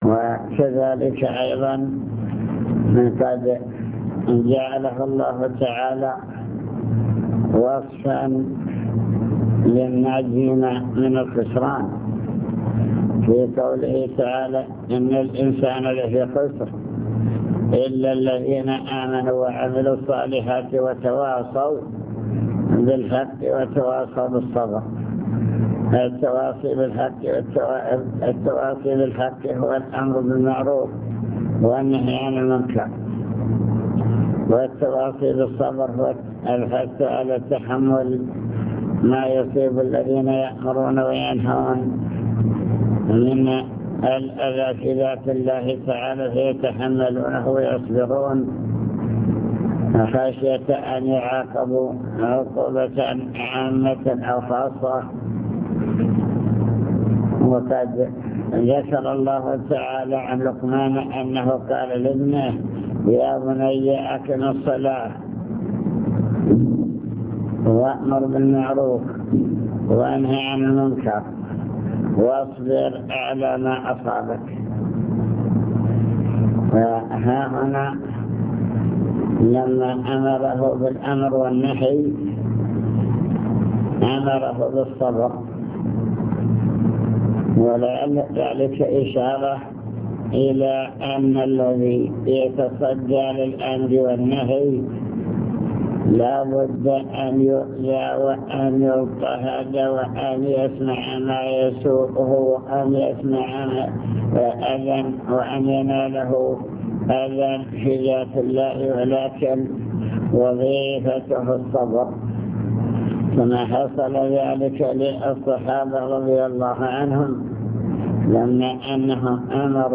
المكتبة الصوتية  تسجيلات - لقاءات  كلمة في رئاسة الأمر بالمعروف الدعوة إلى الله تعالى